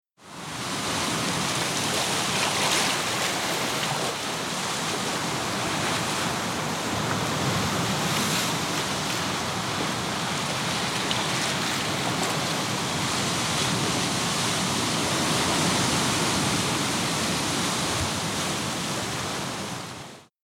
Pacific Tidepool Sounds for Relaxation and Meditation MP3
The Pacific Ocean is mighty and forceful, smashing down onto intertidal rocks with vigor. Here, a stereo microphone is perched on a rock above the tidepools. The waves crash onto the surrounding pools, filling them in all directions around the microphone, creating a rich, thick cauldron of sound.
Pacific-Tidepool-sample2.mp3